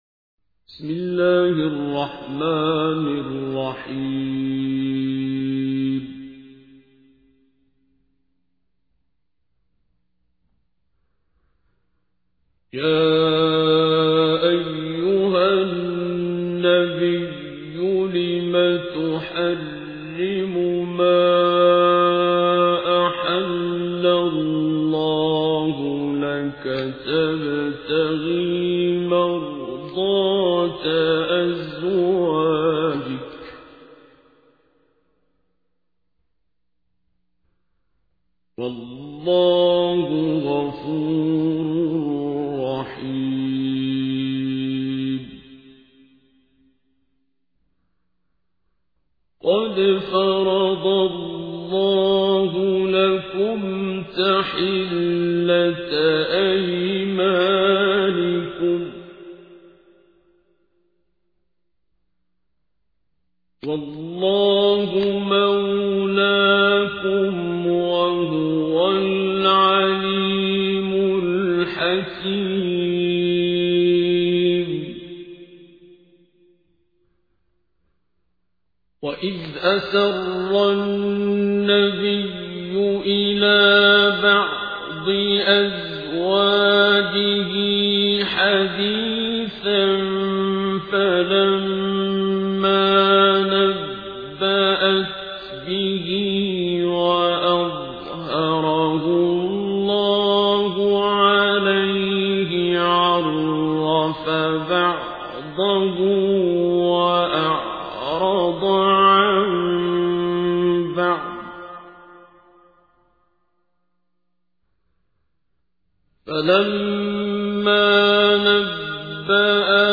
تحميل : 66. سورة التحريم / القارئ عبد الباسط عبد الصمد / القرآن الكريم / موقع يا حسين